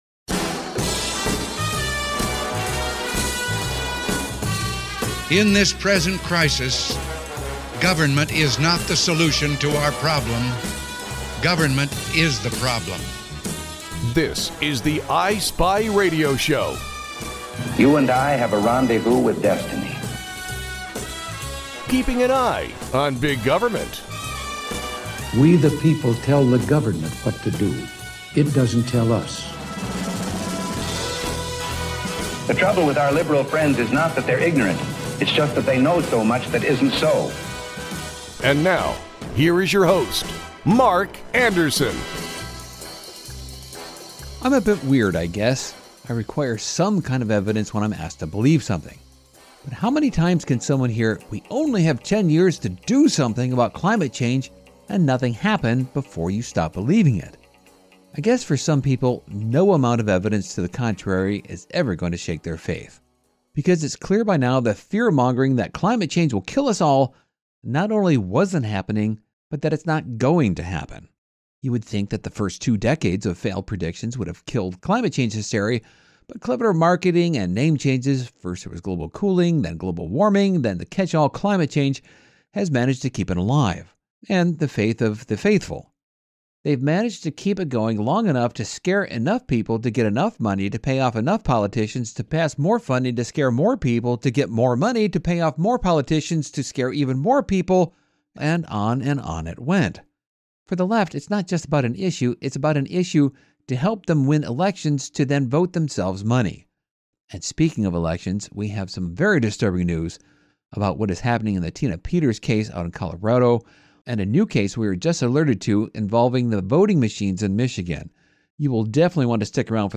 The I Spy Radio Show airs weekends, seven times over the weekend, on seven different stations.